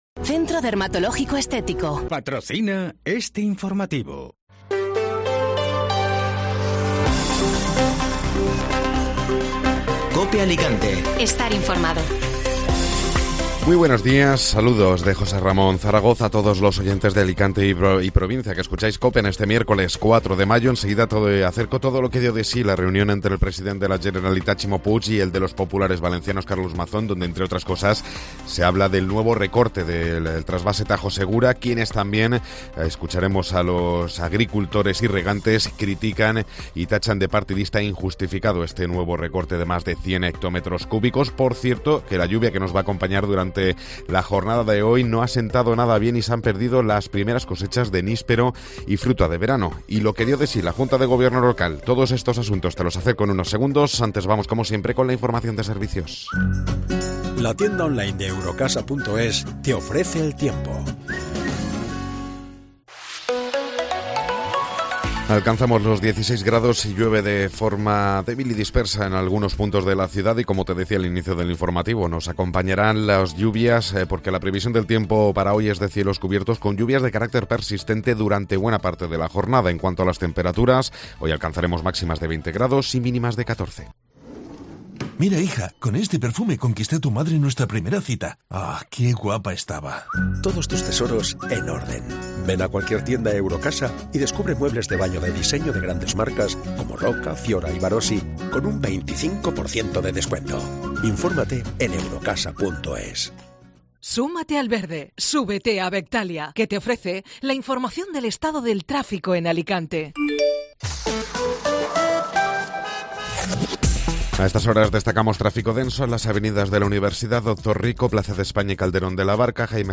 Informativo Matinal (Miércoles 4 de Mayo)